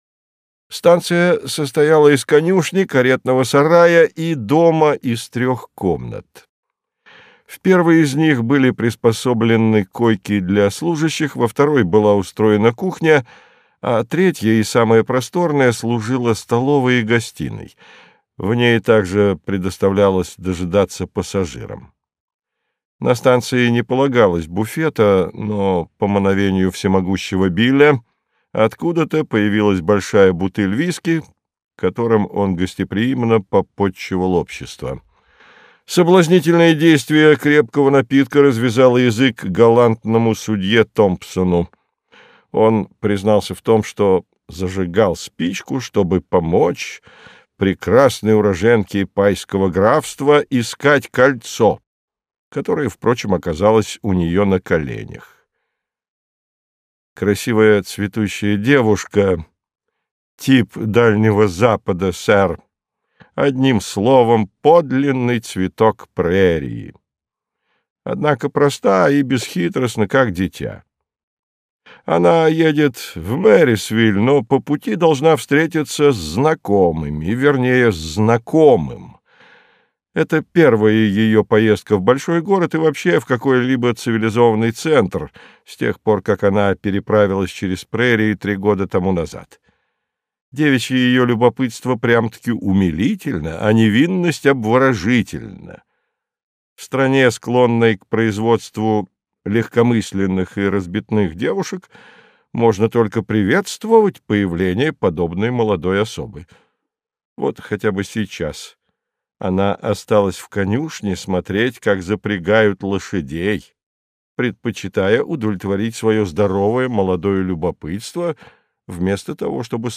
Аудиокнига Новеллы | Библиотека аудиокниг